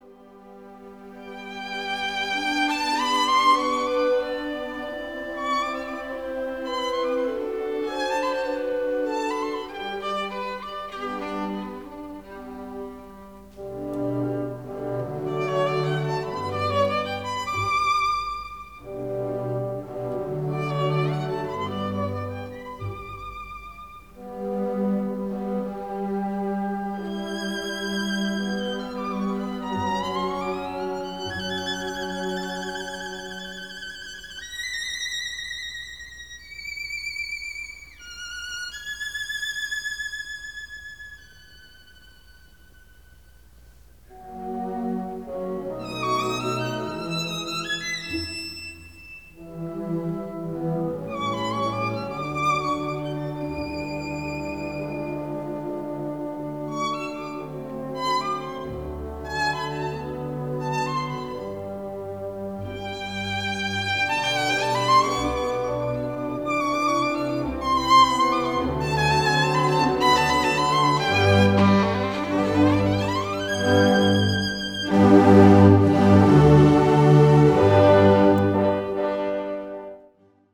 violin
Boston Symphony Orchestra Jascha Heifetz, violin; Charles Munch
Beethoven Violin 1 - Boston - Munch.mp3